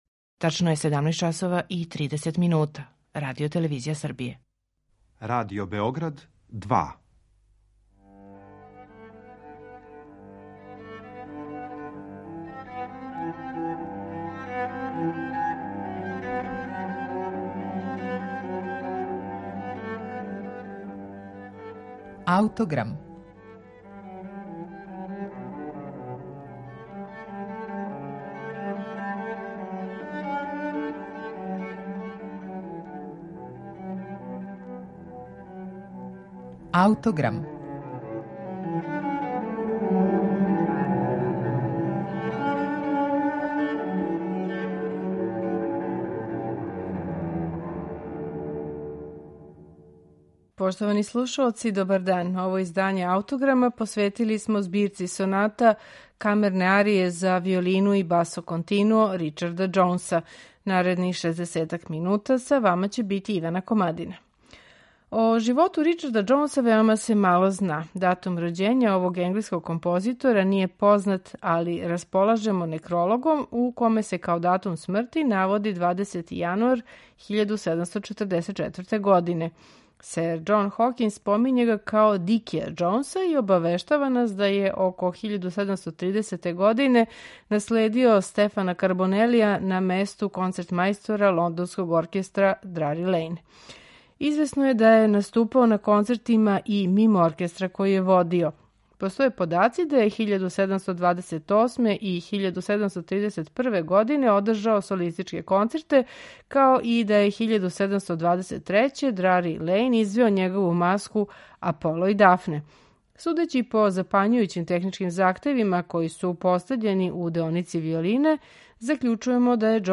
Ричард Џоунс: Камерне арије за виолину и басо континуо
Ту се могу препознати многи утицаји, лакоћа француских игара, трагика Леклерових страница, импровизациони мелизми италијанског стила, екстравагантни покрети Верачинија.
на оригиналним инструментима епохе
виолинисткиња
виолончелиста
чембалисткиња